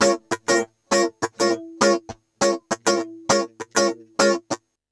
100FUNKY01-L.wav